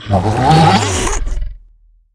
alien_language_03.ogg